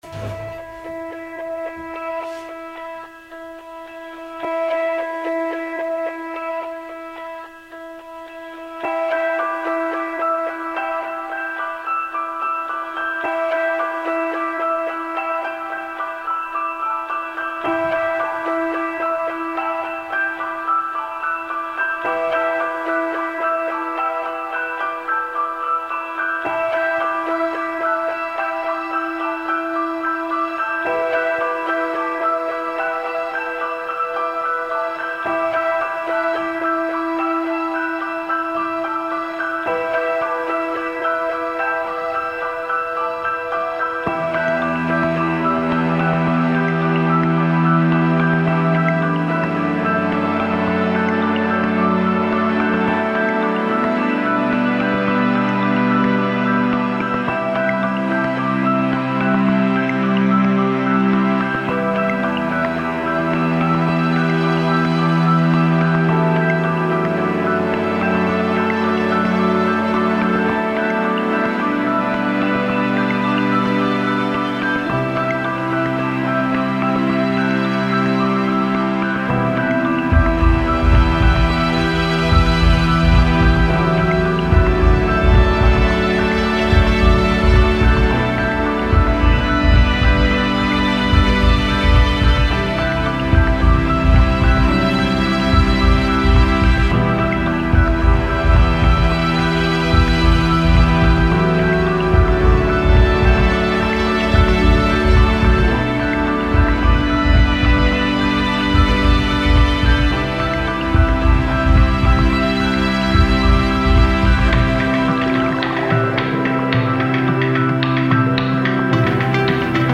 Musikstück mit cinematischer Note.